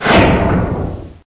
door_close_2.ogg